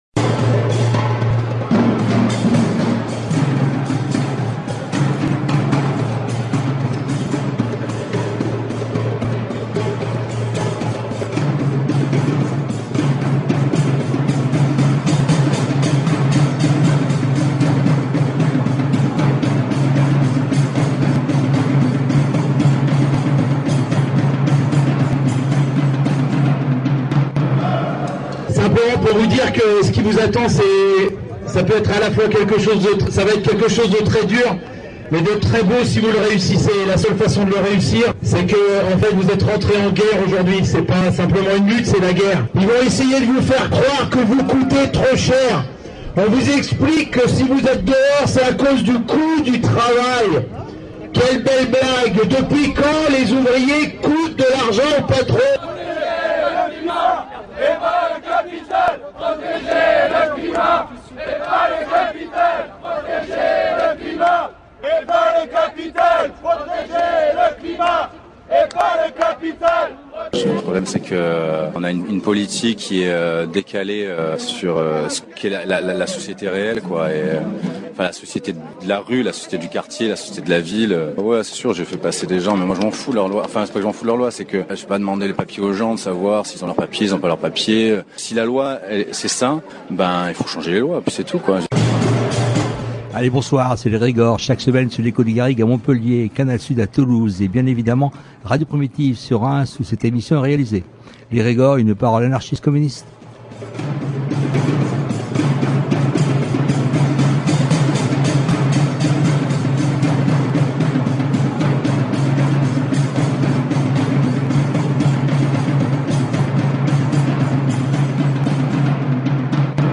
À la suite de la présentation du programme “Quartiers de demain”, le collectif Stop Démolition a écrit une lettre ouverte au président de la République. Nous entendrons dans cette heure d’émission, différentes interventions qui ont rythmé cette conférence de presse. classé dans : société Derniers podcasts Découvrez le Conservatoire à rayonnement régional de Reims autrement !